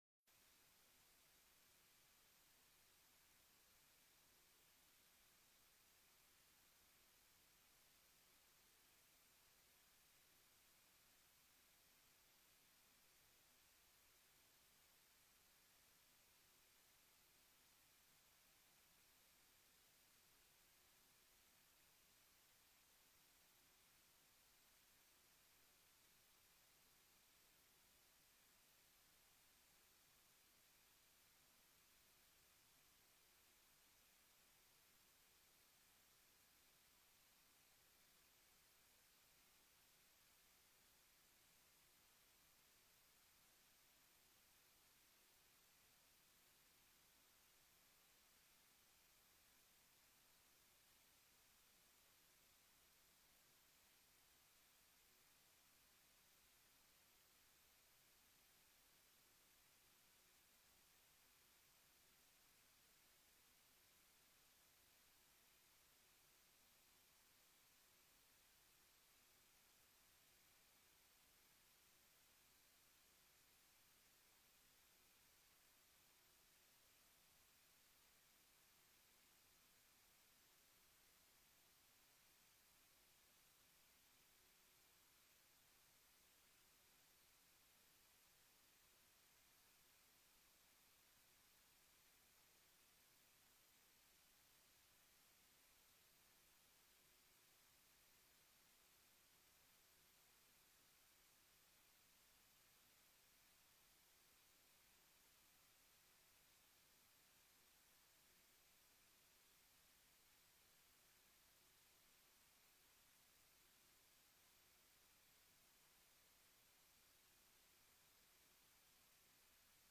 Locatie: Raadzaal